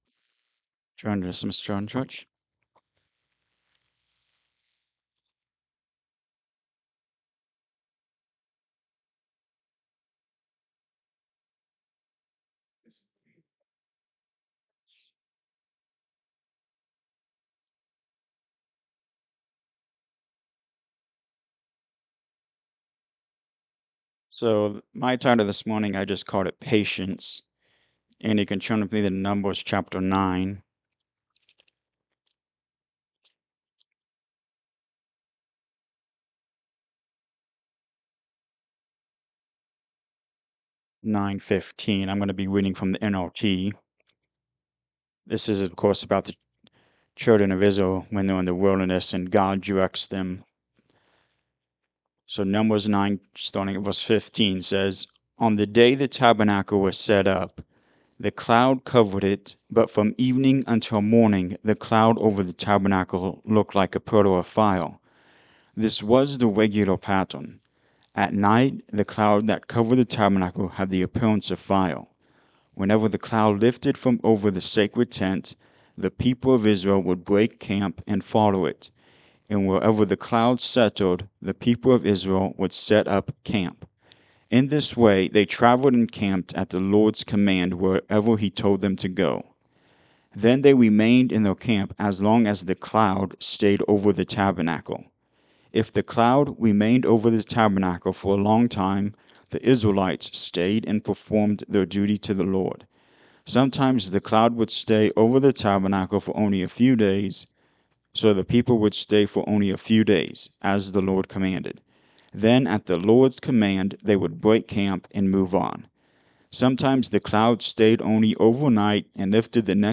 Patience and God’s Word Series Sermon 10 Faith in His Will
Numbers 9:15-23 Service Type: Sunday Morning Service Patience.